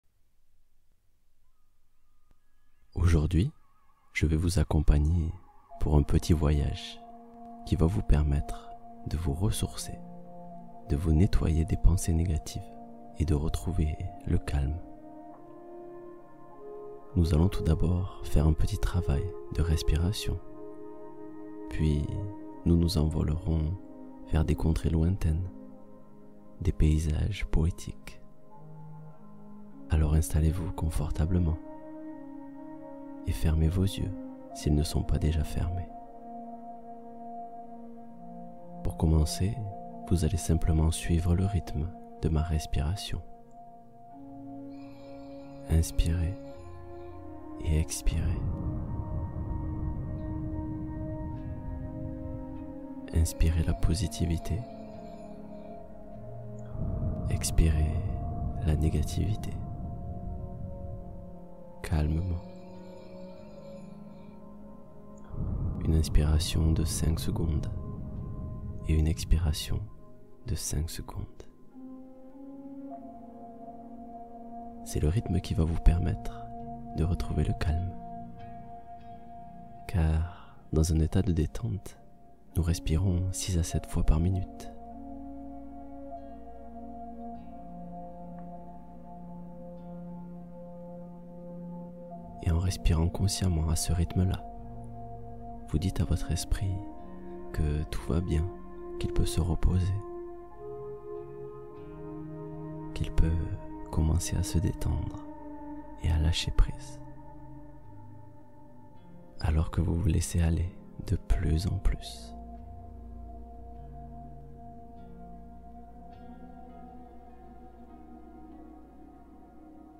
Respiration guidée : calmer le mental et inviter la paix